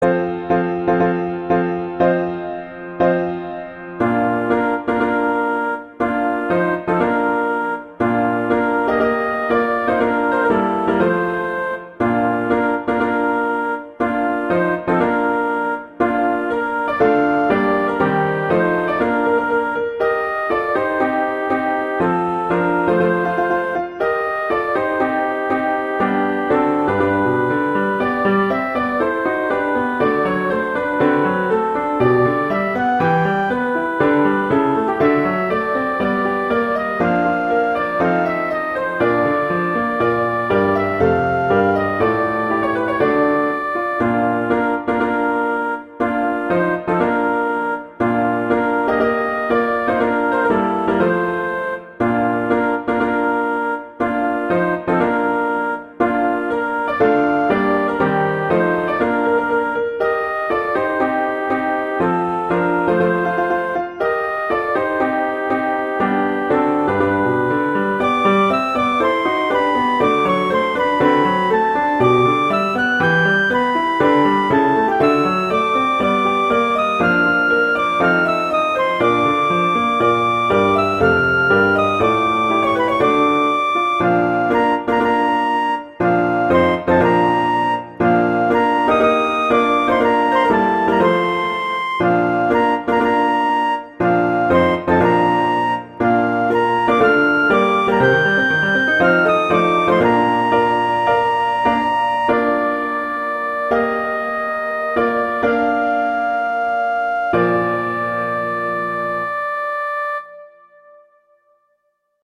Instrumentation: flute & piano (organ)
arrangements for flute and piano (organ)